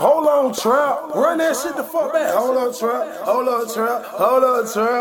Hold on Trap.wav